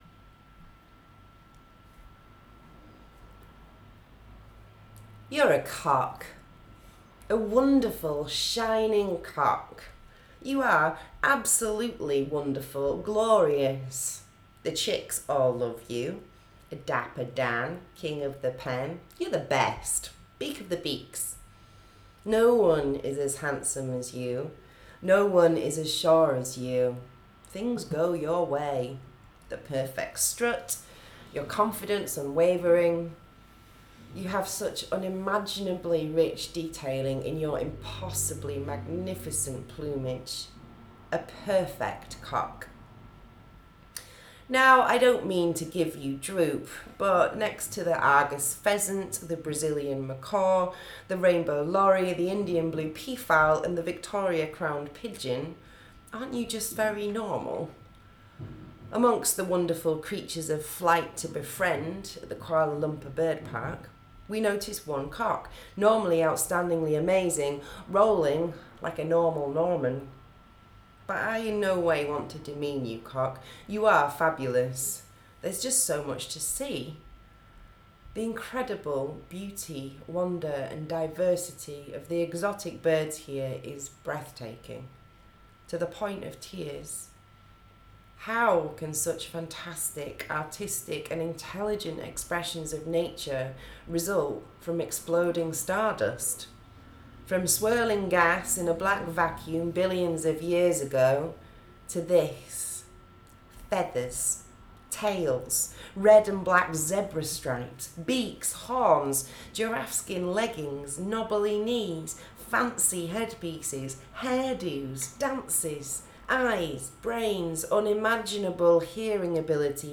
kl-bird-park.wav